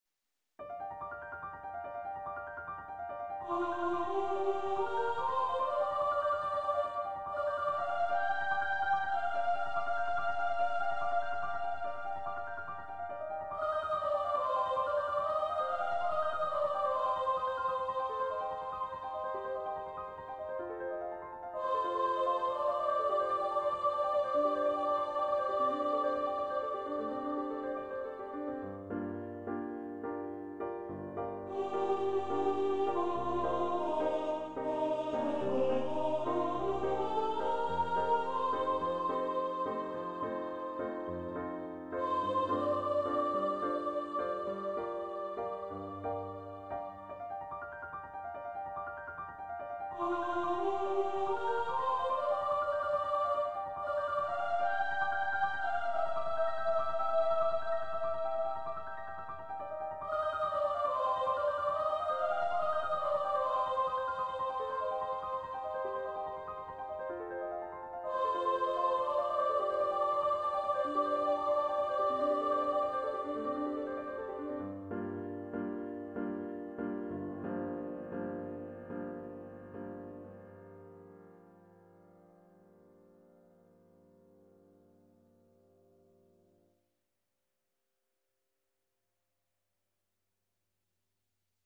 Voice and Piano
Composer's Demo